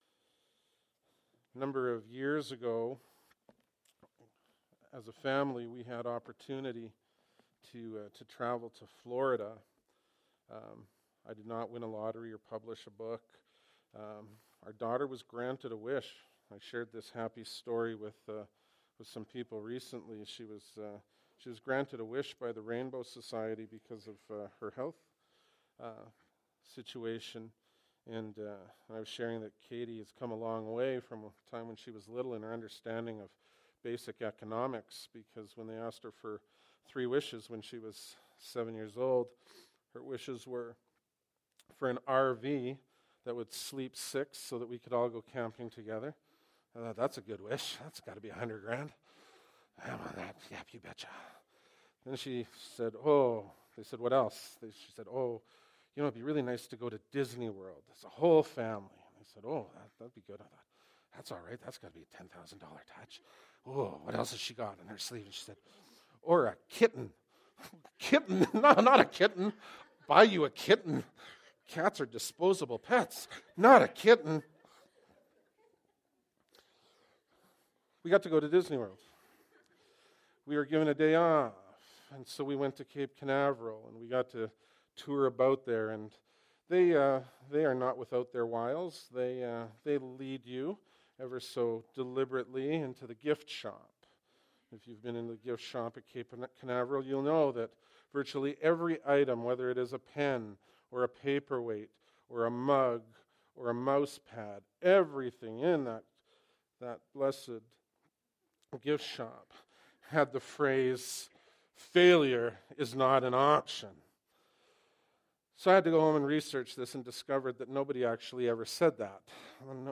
Bible Text: Hebrews 12:14-21, 28-29 | Preacher